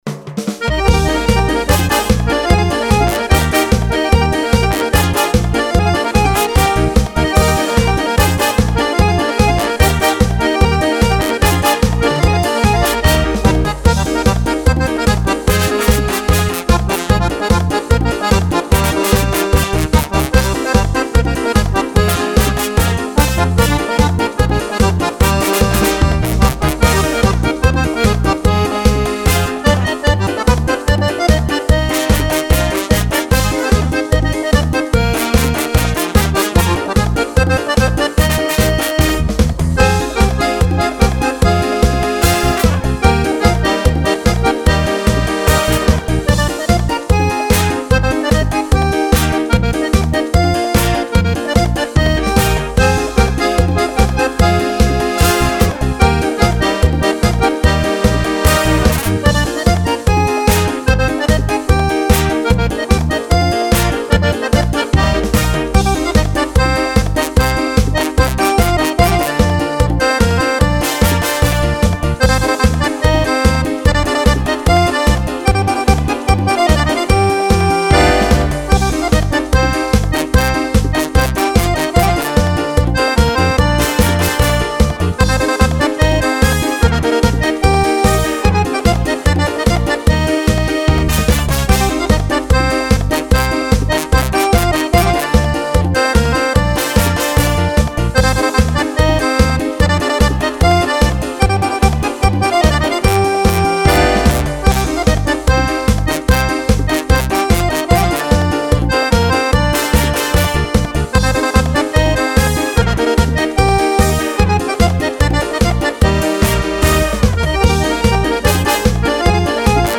Tre brani per Fisarmonica di grande effetto.
Merengue